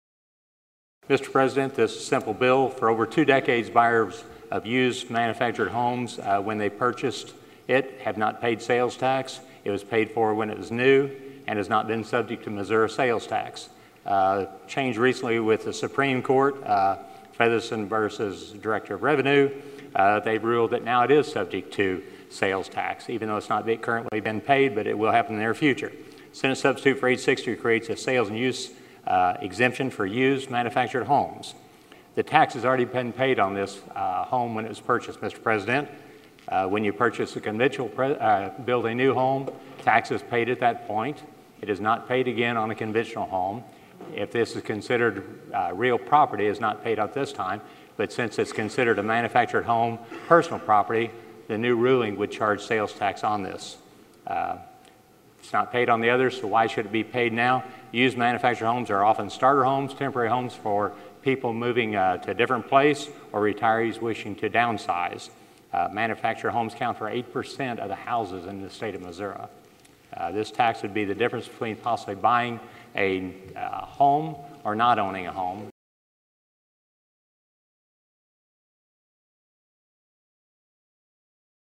The audio and video below is taken from the Missouri Senate floor on April 15, 2014, and features Sen. Cunningham presenting Senate Bill 860.
Senator Cunningham presents Senate Bill 860 on the floor of the Missouri Senate. The measure would create a sales and use tax exemption for used manufactured homes.